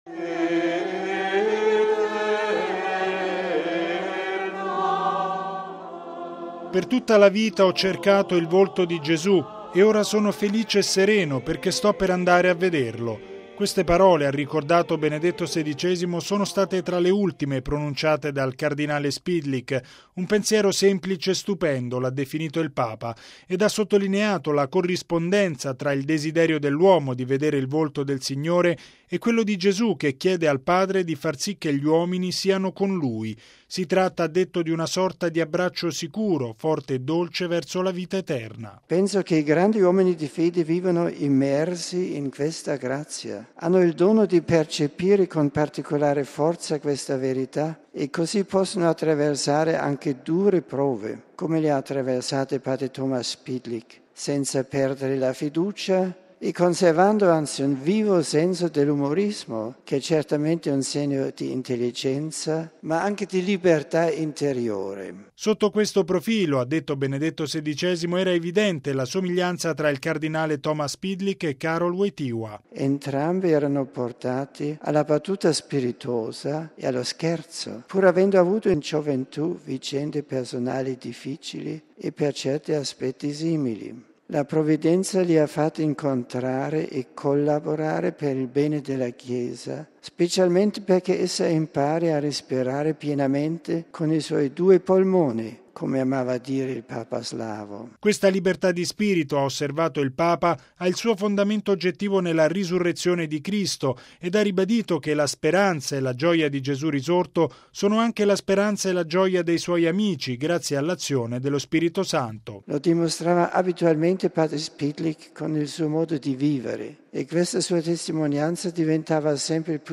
(canti)